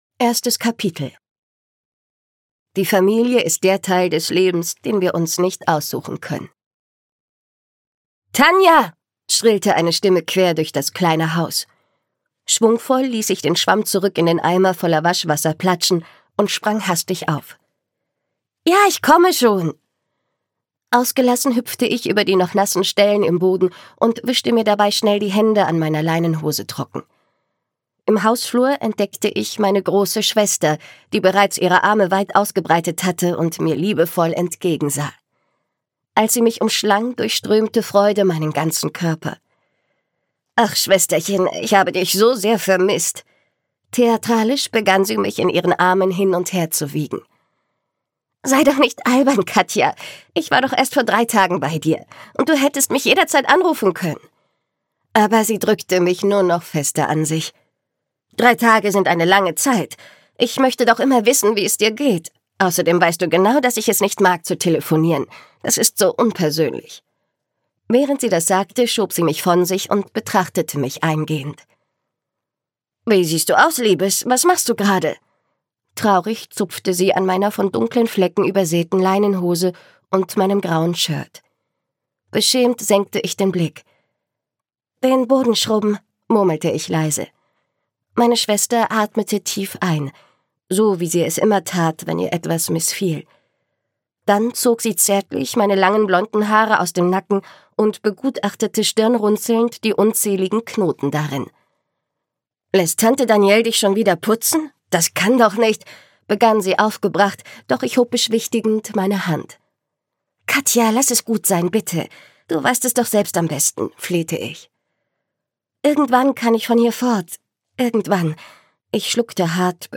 Royal 1: Ein Leben aus Glas - Valentina Fast - Hörbuch